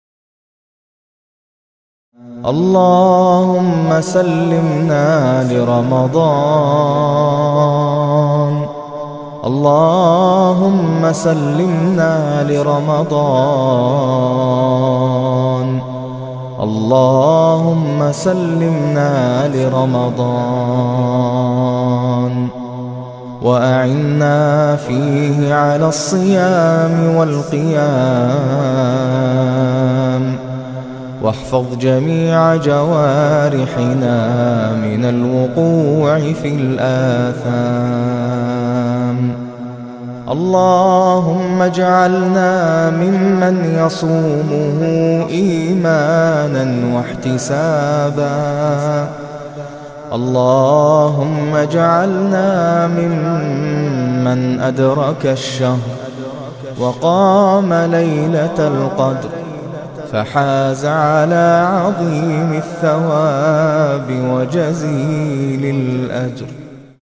الشيخ-ناصر-القطامي-دعاء-اللهم-سلمنا-لرمضان.mp3